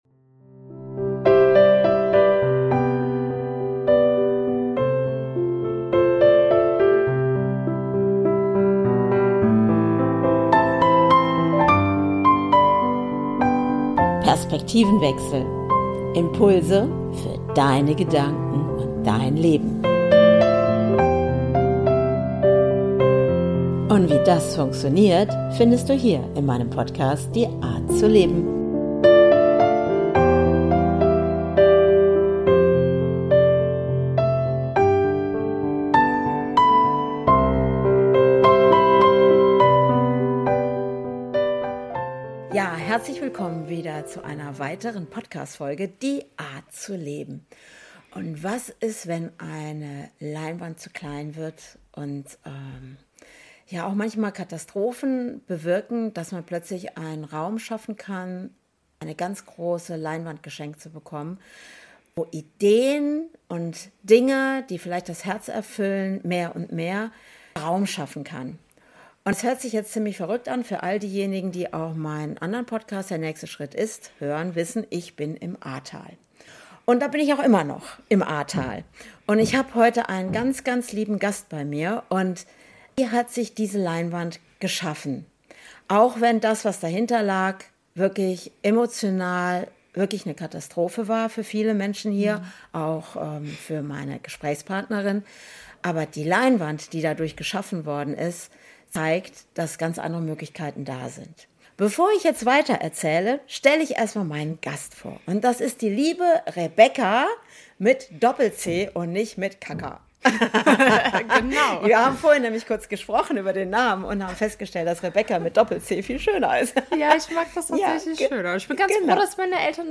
#75 Die Flut kam ... Ein Interview